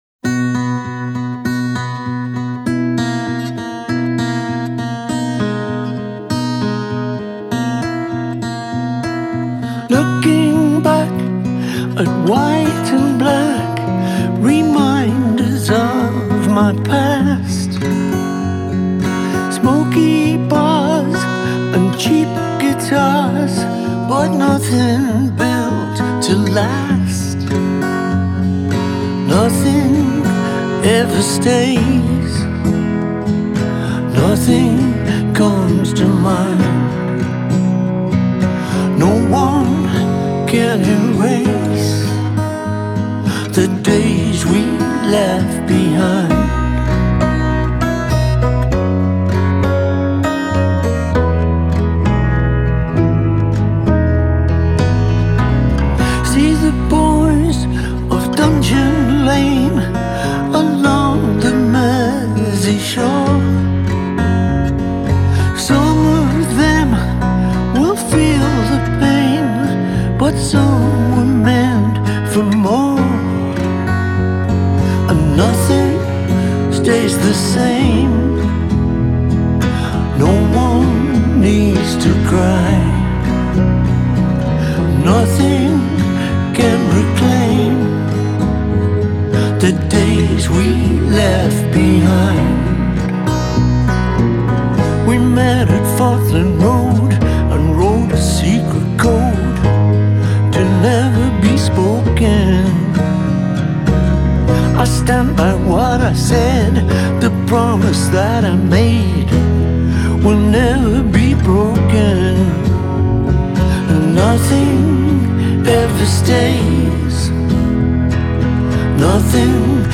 The first single is a good song, and definitely nostalgic.